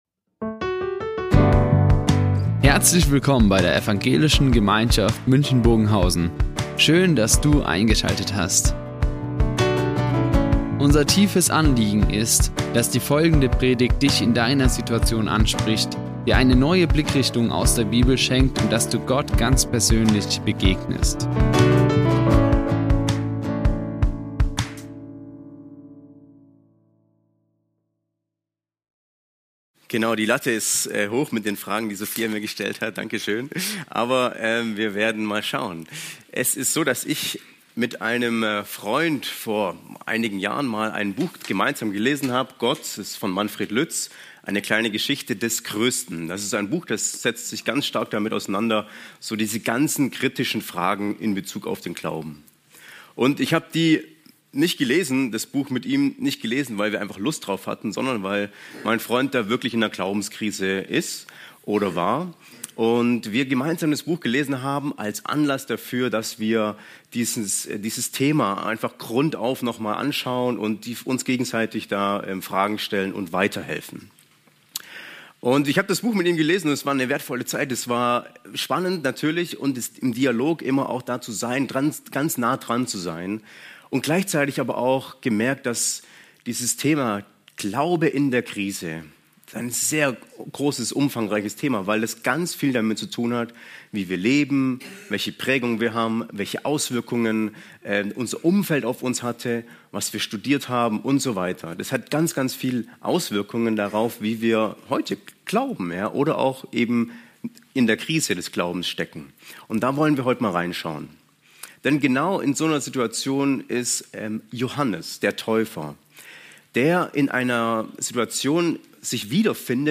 EinSPRUCH gegen Glauben in der Krise | Predigt Matthäus 11,1-6 ~ Ev. Gemeinschaft München Predigten Podcast
Die Aufzeichnung erfolgte im Rahmen eines Livestreams.